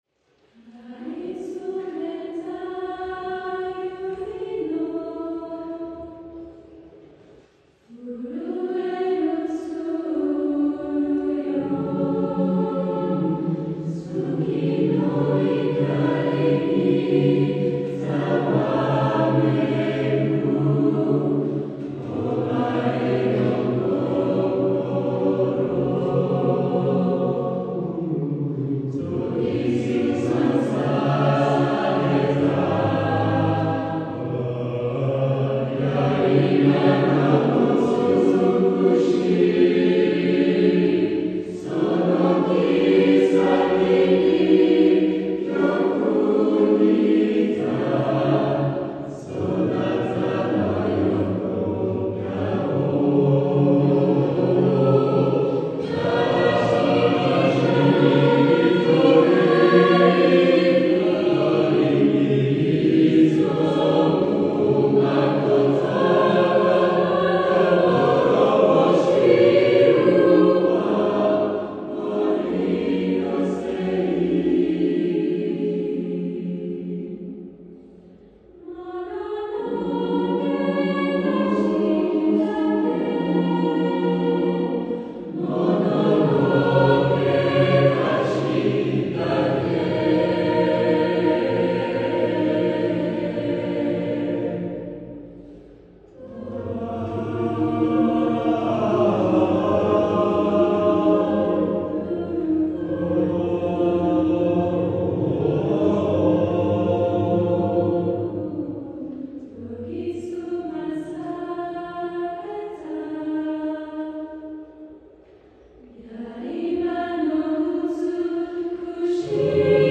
Arrangement pour chœur
Audio complet (voix réelles)